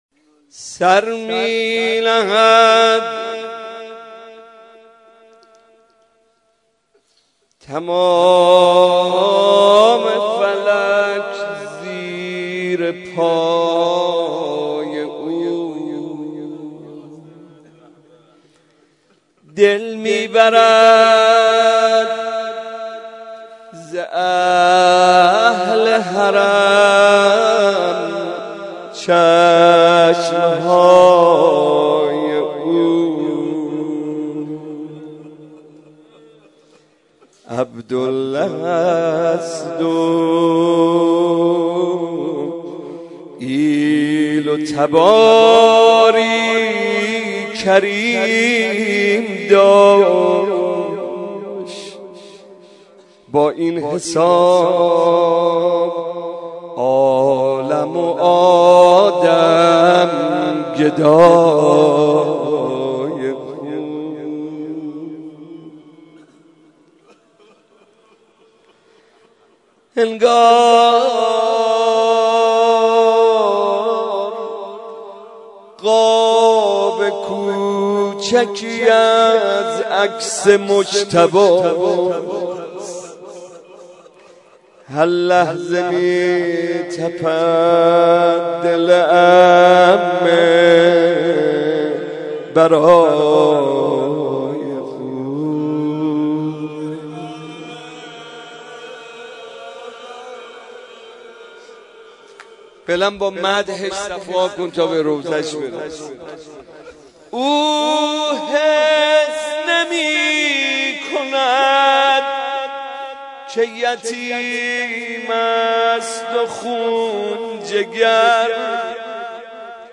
مراسم شب پنجم ماه مبارک رمضان
مداحی